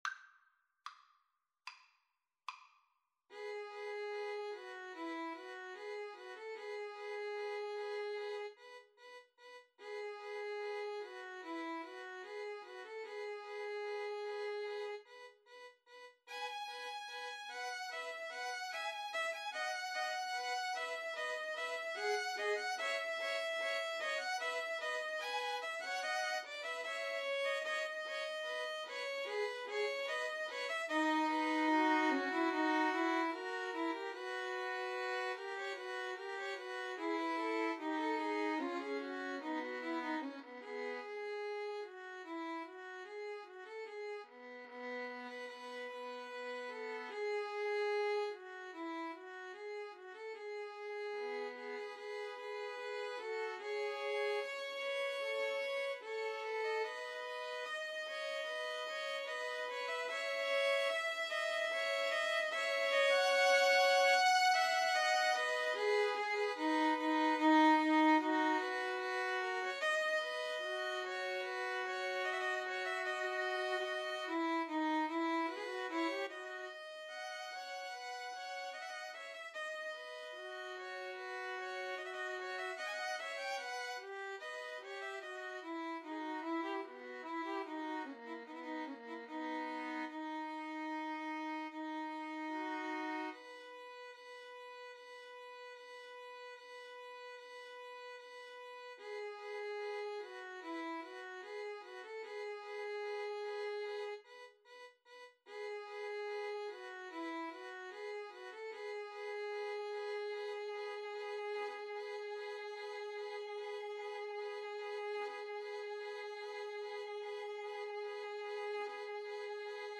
Free Sheet music for Violin Trio
4/4 (View more 4/4 Music)
~ = 74 Moderato
E major (Sounding Pitch) (View more E major Music for Violin Trio )